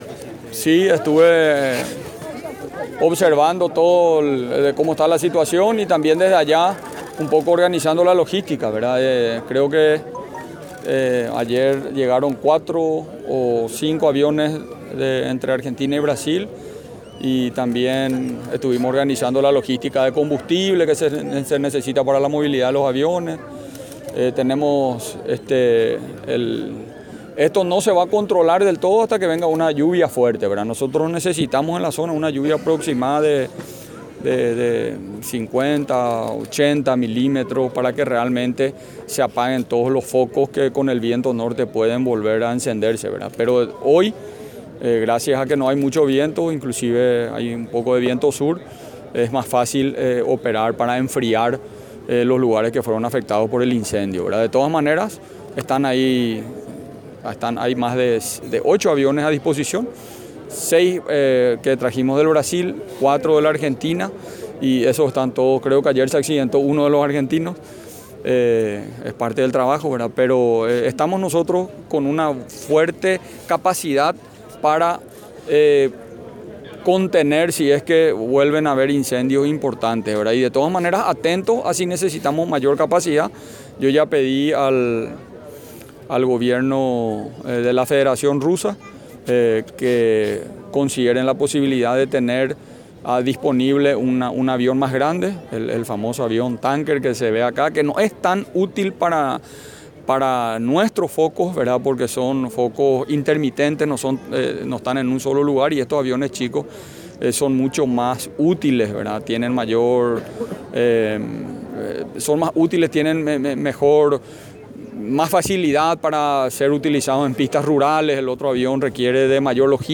“Todos los ministros están siempre a disposición del presidente, pero en este momento Juan Ernesto Villamayor se queda”, ratificó ante los medios de prensa presentes hoy en un acto oficial en la ciudad de Itá.